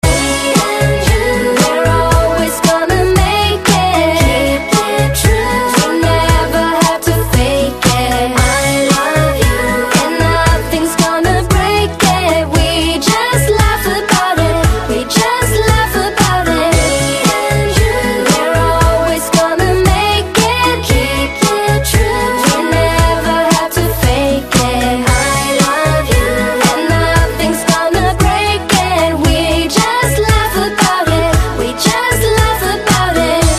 M4R铃声, MP3铃声, 欧美歌曲 134 首发日期：2018-05-15 17:43 星期二